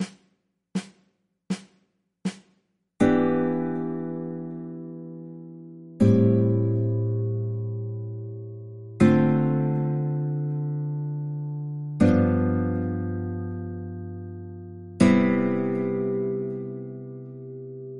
Jazz Progressions
You can start this one with a I-ii-V first, but the key is that you are expanding on the vi-ii-V-I.
iii-vi-ii-V-I Chord Progression
chord_progressions_iii-vi-ii-V-I.mp3